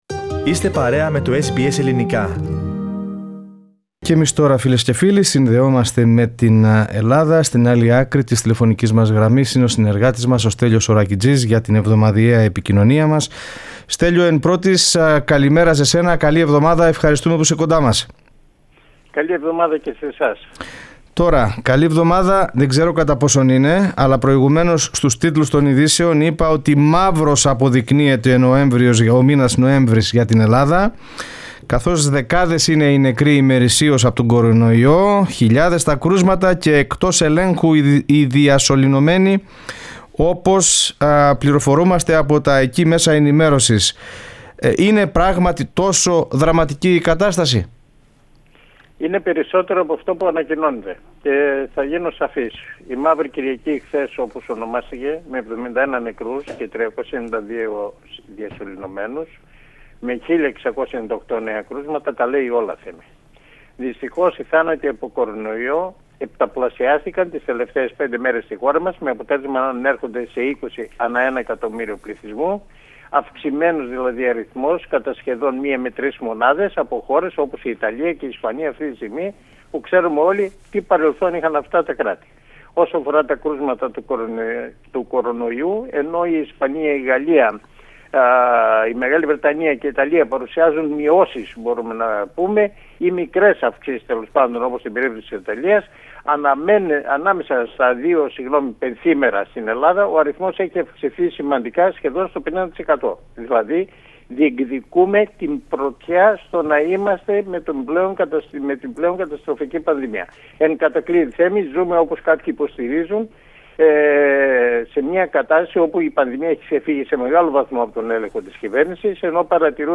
την εβδομαδιαία ανταπόκριση από την Ελλάδα